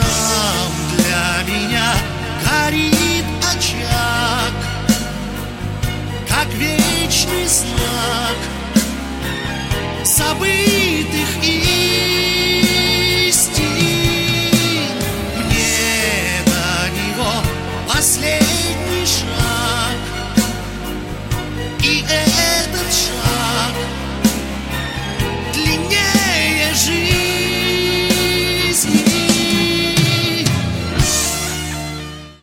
• Качество: 128, Stereo
эстрада